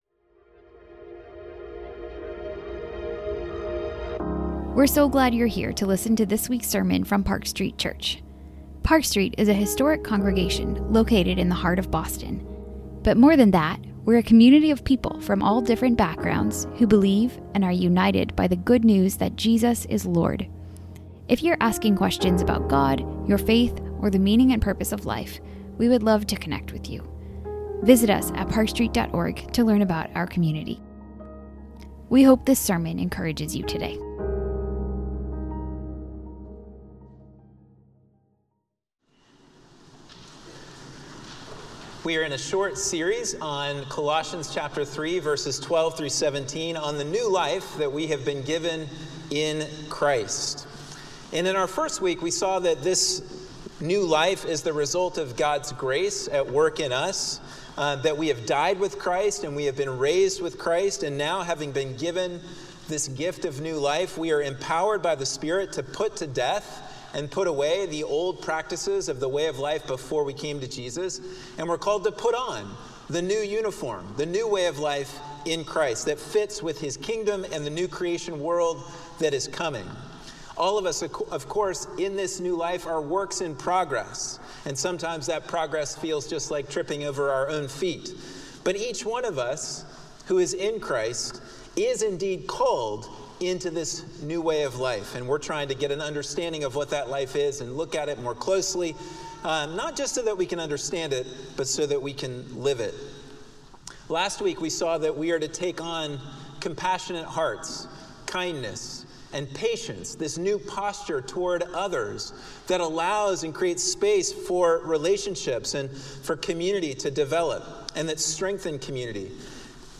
Sermons - Park Street Church